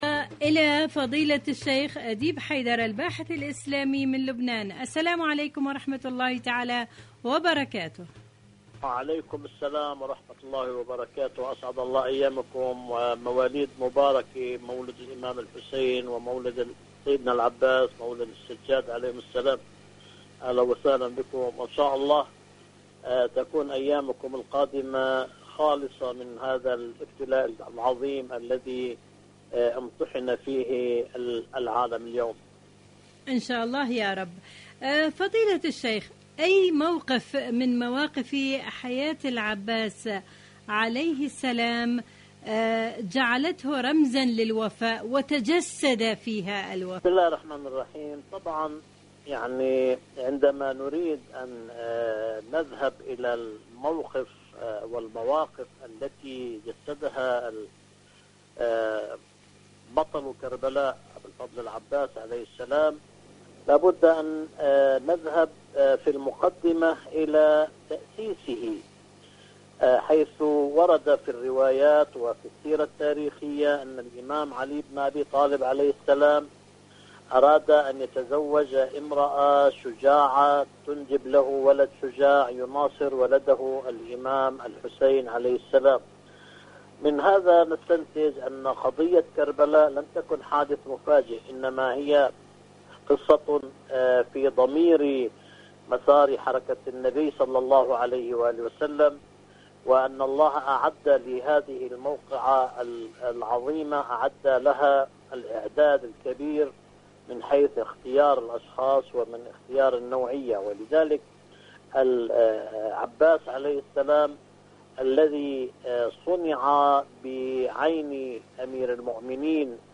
إذاعة طهران العربية مقابلات إذاعية